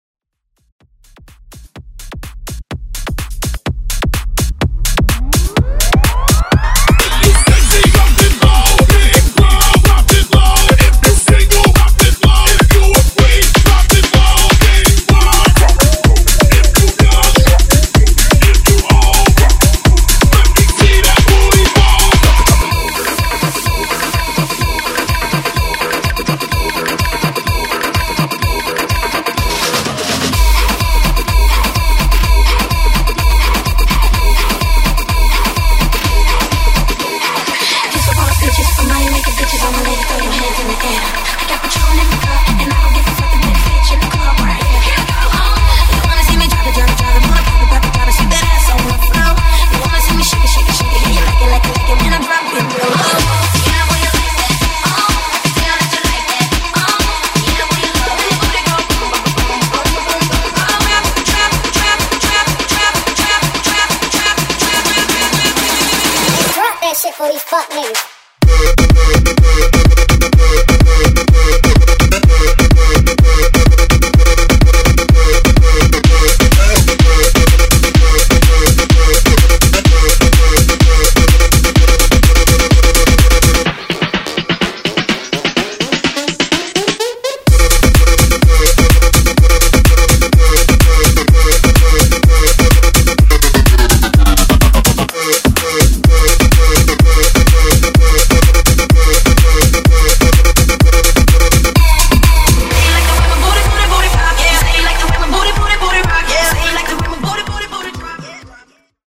Genres: EDM , MASHUPS , TOP40
Clean BPM: 130 Time